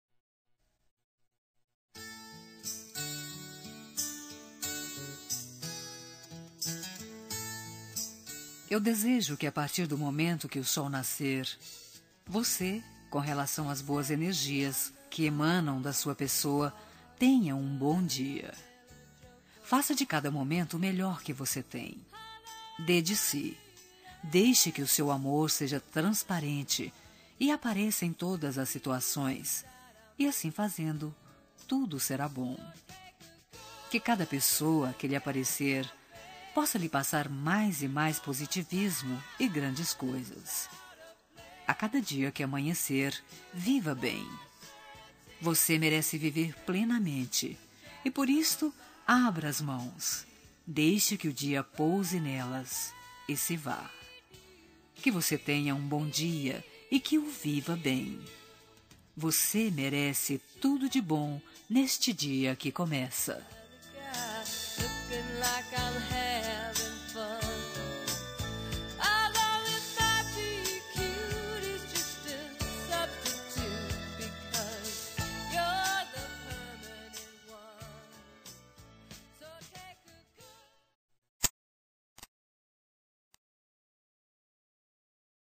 Telemensagem de Bom Dia – Voz Feminina – Cód: 6315 – Geral